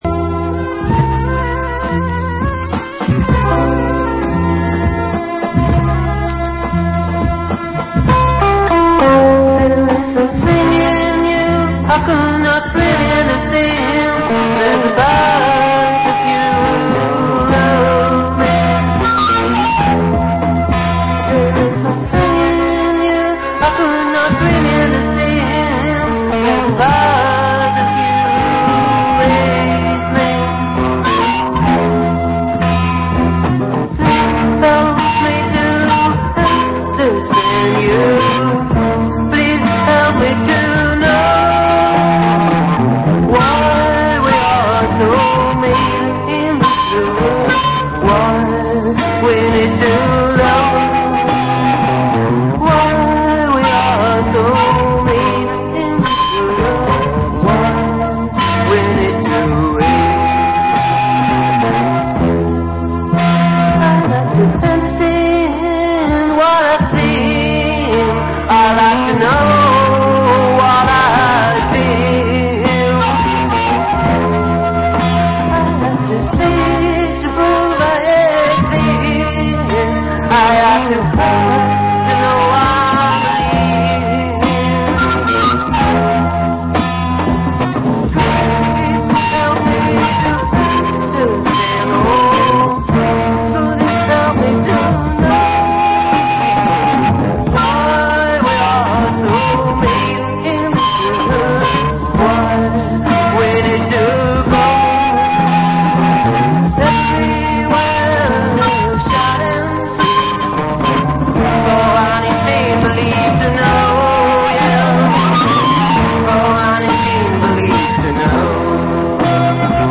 Weekly show live from Brooklyn.